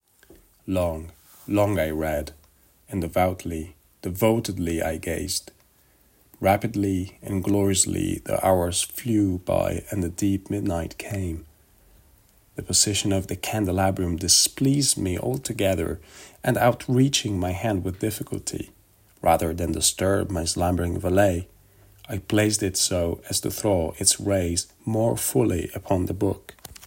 American-2.mp3